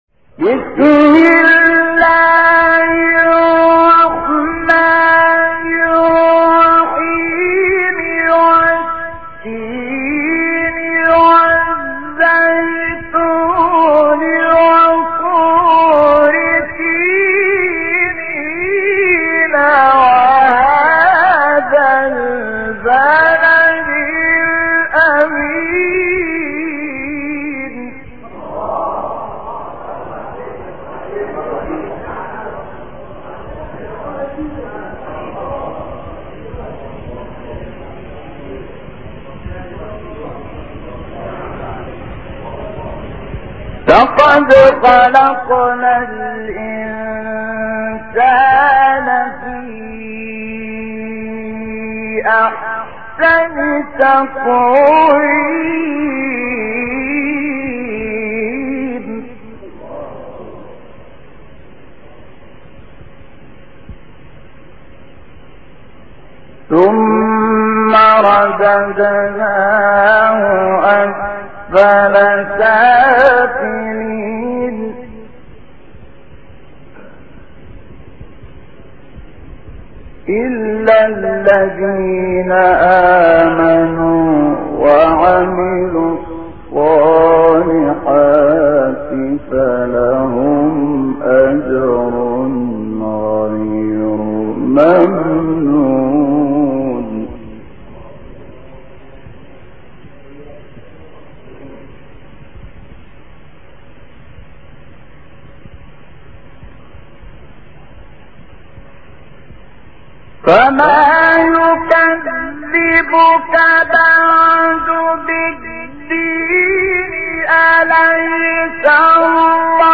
تلاوت سوره تین و علق استاد شحات محمد انور | نغمات قرآن | دانلود تلاوت قرآن